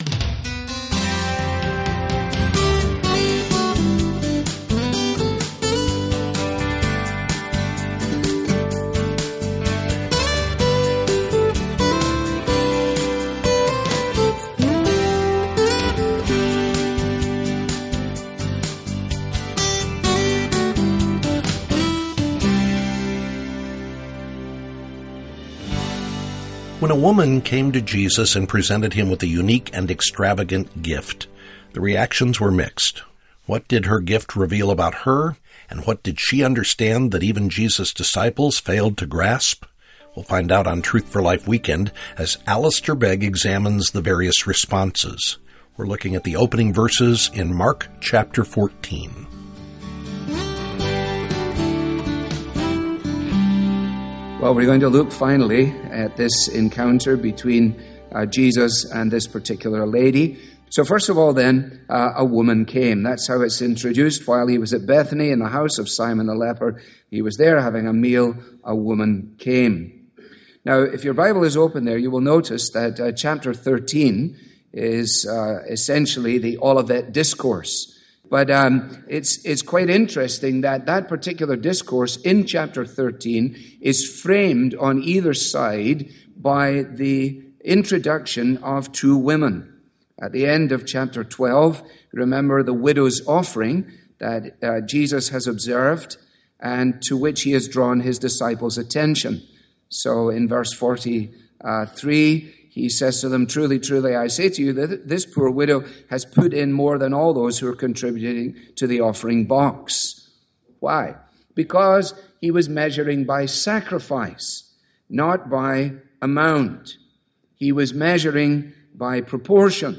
Daily teaching from Truth For Life relies on your prayer and financial support.